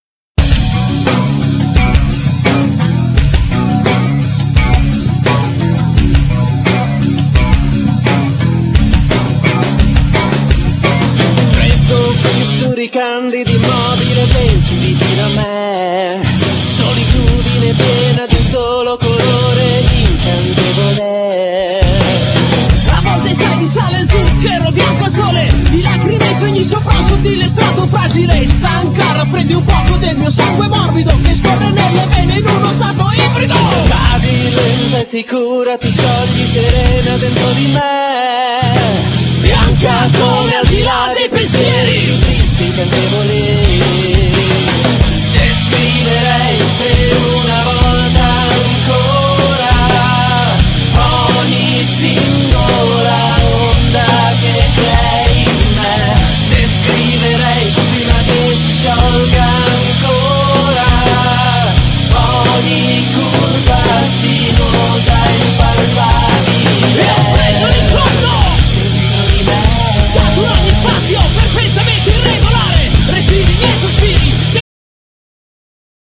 Genere : Pop
Qualitą di registrazione piuttosto approssimativa.